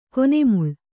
♪ kone muḷ